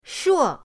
shuo4.mp3